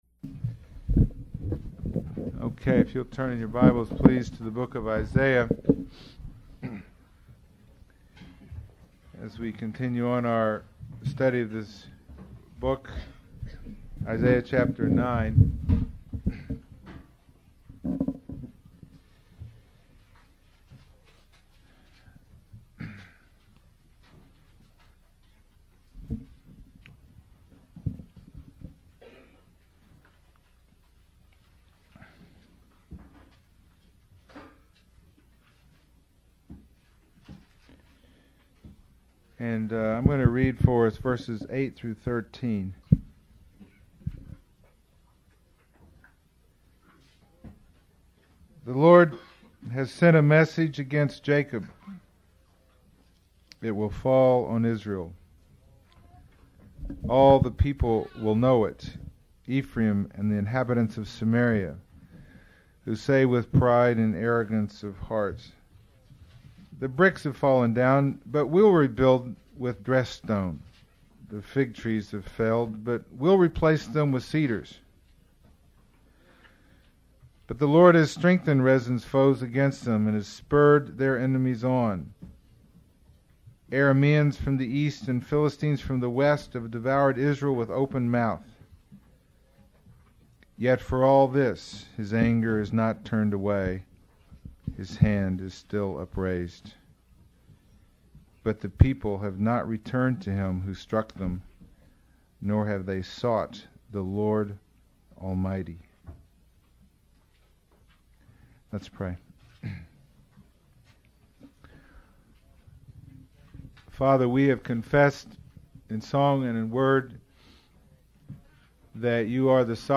Sermon 40